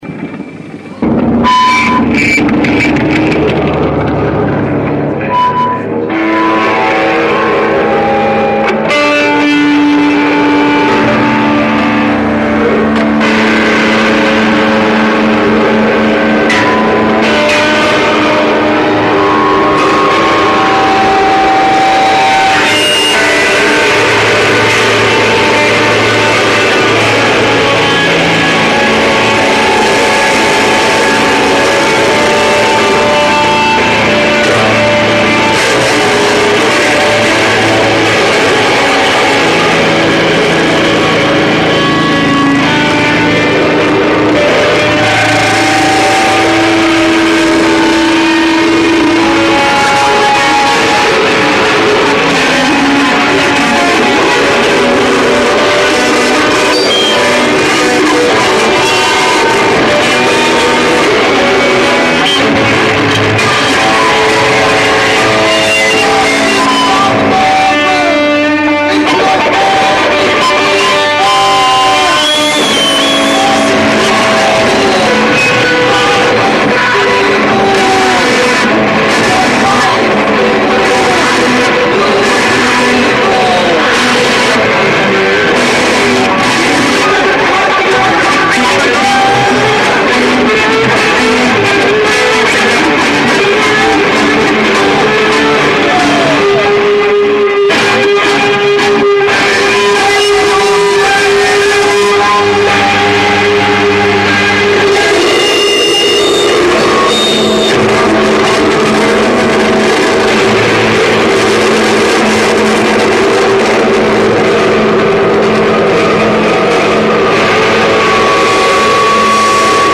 live (2006)